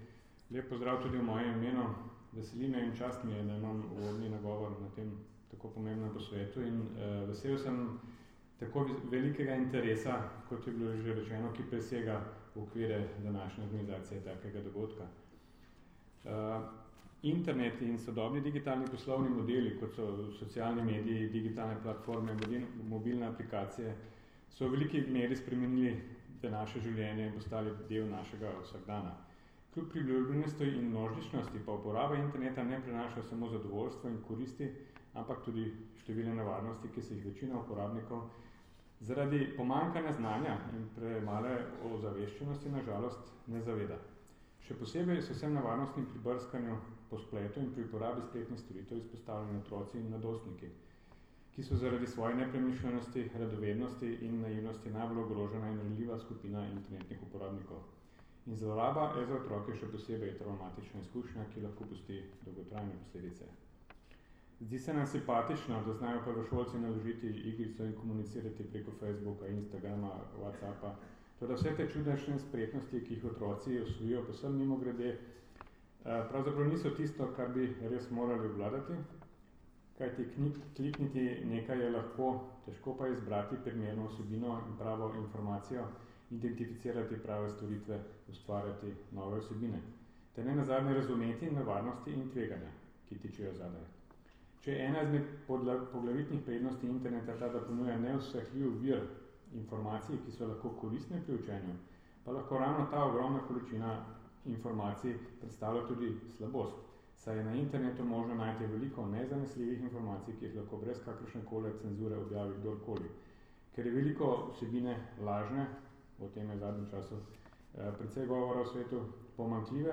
Zvočni posnetek nagovora